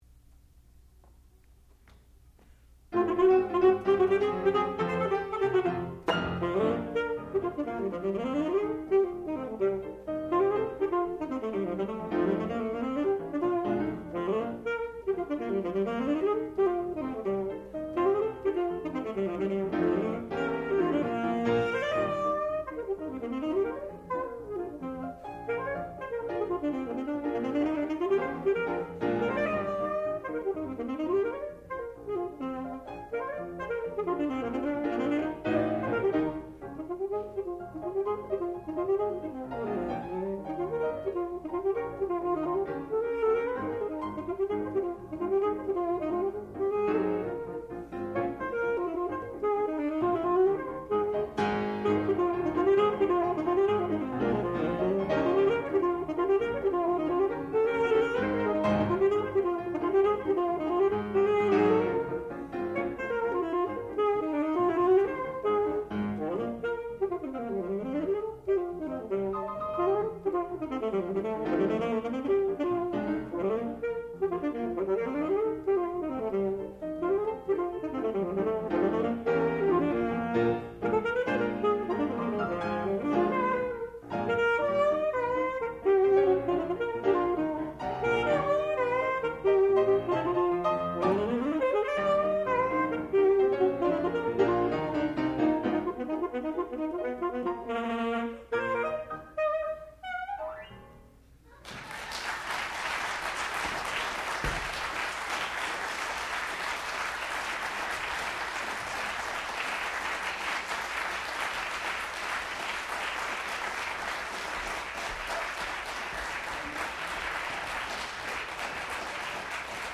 sound recording-musical
classical music
saxophone
piano